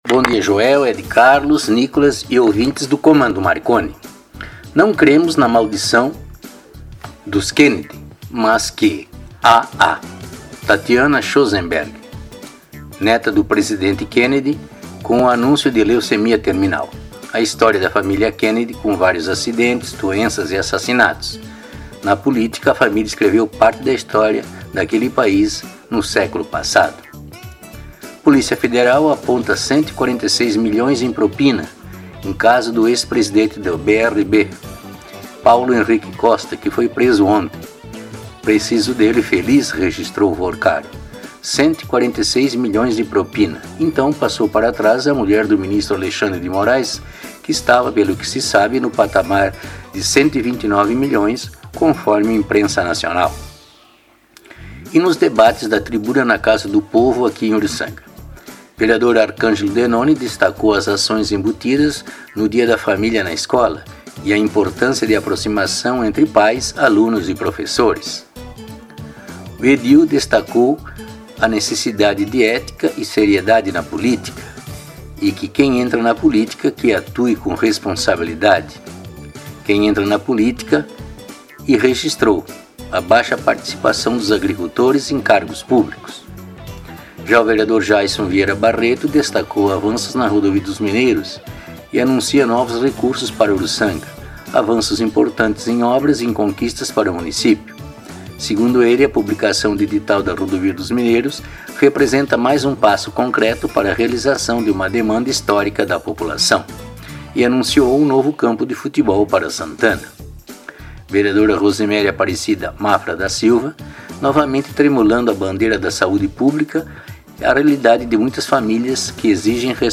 A atração é apresentada de modo espirituoso e com certas doses de humorismo e irreverência, além de leves pitadas de ironia quando necessárias.
As crônicas são veiculadas pela Rádio Marconi 99.9 FM nas segundas, quartas e sextas-feiras durante os programas Comando Marconi e Giro Final.